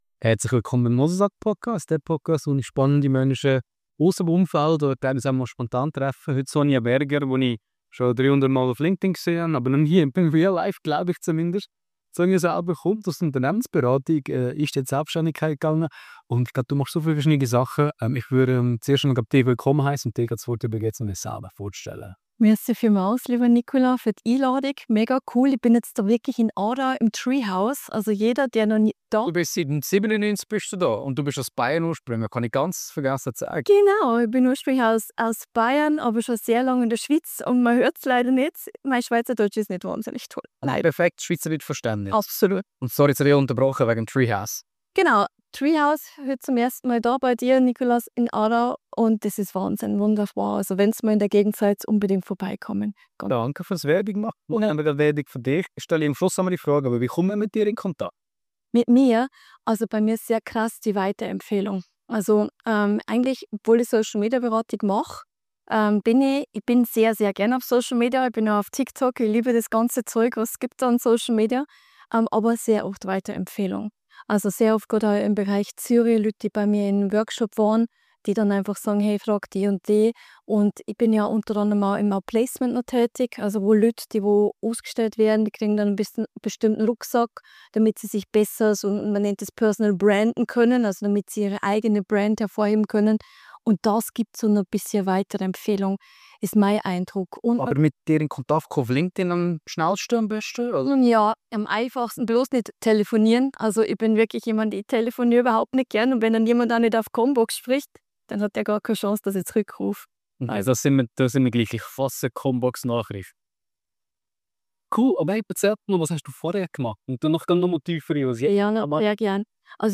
Ein spannendes Gespräch über Selbständigkeit, LinkedIn, Sichtbarkeit und persönliches Wachstum.